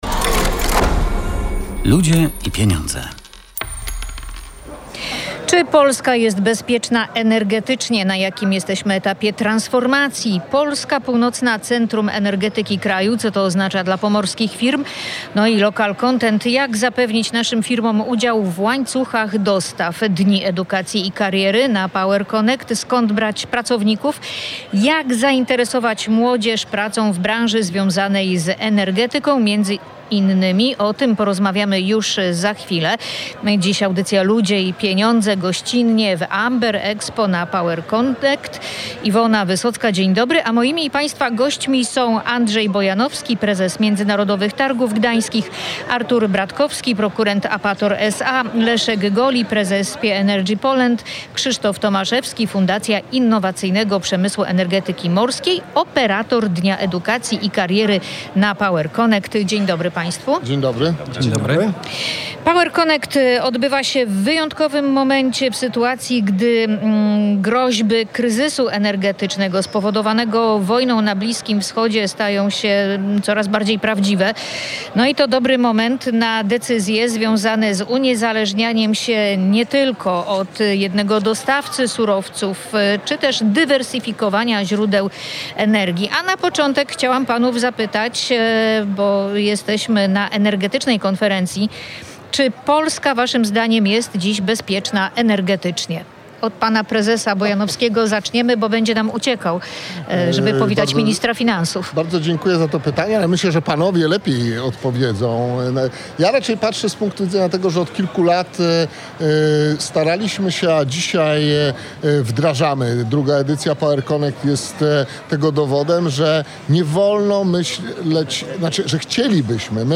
Na ten temat dyskutowali goście specjalnego wydania audycji „Ludzie i Pieniądze” na PowerConnect